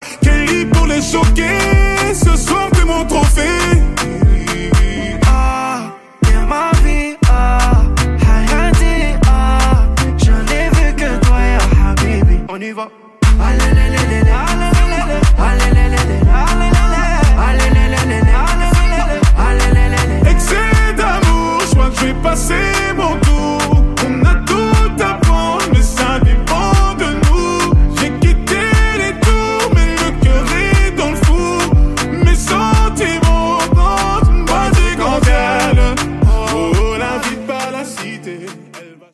Categoria Rap/Hip Hop